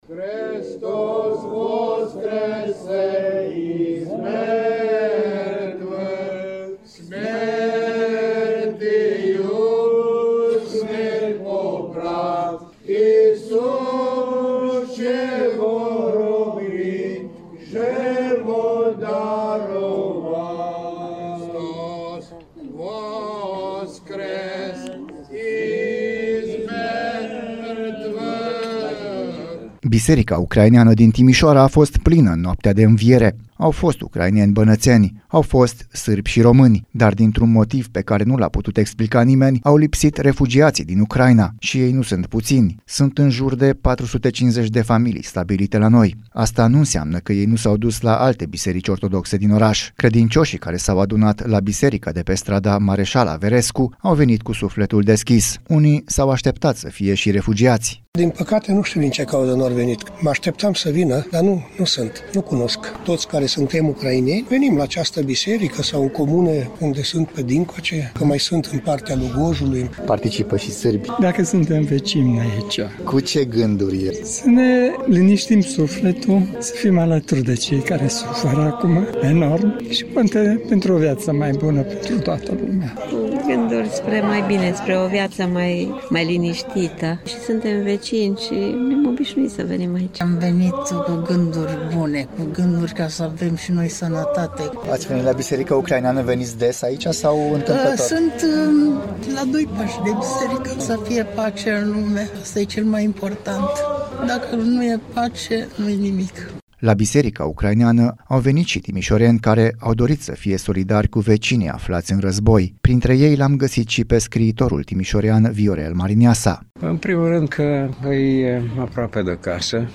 Slujba de Înviere la biserica ucraineană din Timișoara. Au lipsit însă refugiații din calea războiului
Biserica ucraineană din Timişoara a fost plină în noaptea de Înviere.